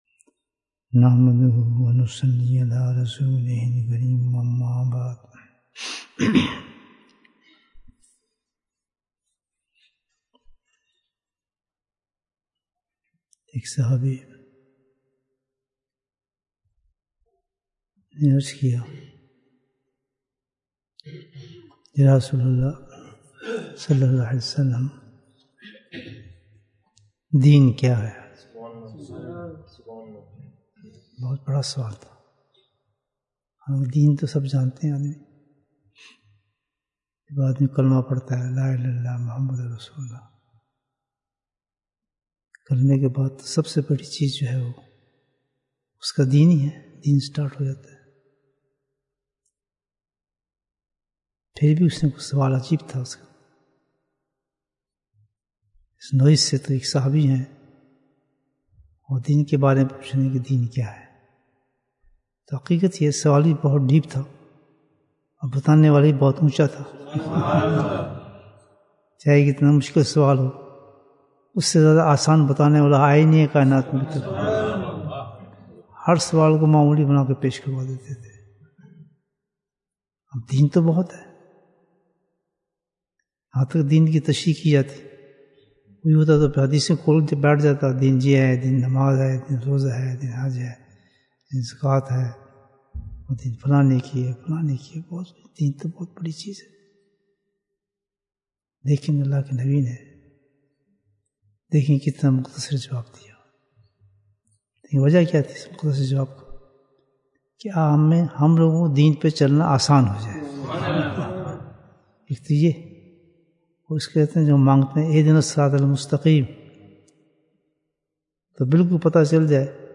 Bayan, 49 minutes24th April, 2022